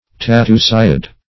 Search Result for " tatusiid" : The Collaborative International Dictionary of English v.0.48: Tatusiid \Ta*tu"si*id\, n. (Zool.) Any armadillo of the family Tatusiidae , of which the peba and mule armadillo are examples.